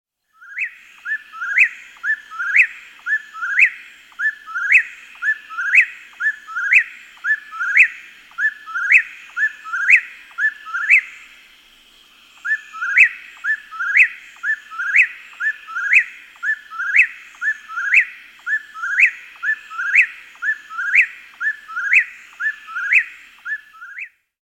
Whip-poor-will song
It was a clear November night, the stillness punctuated by the distinctive sound of a lone Whip-poor-will perched in a nearby tree.
Whipoorwill.mp3